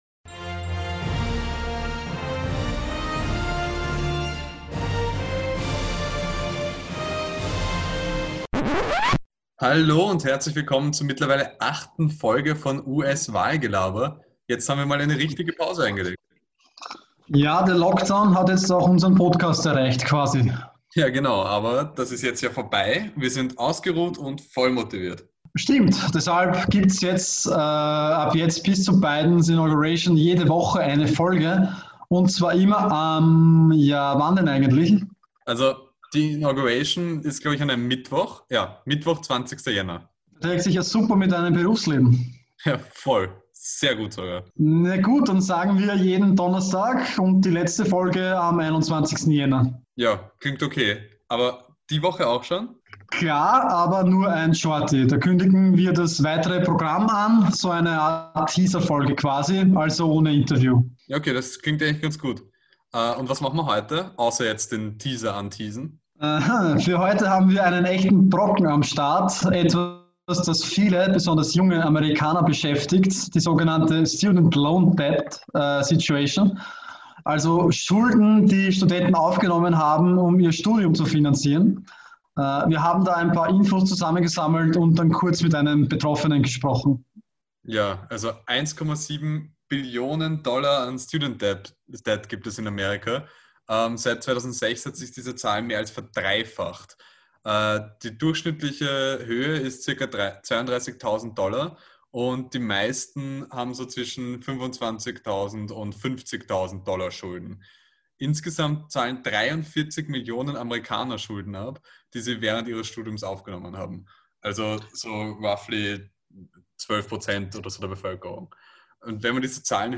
in den USA angerufen und ein wenig geplaudert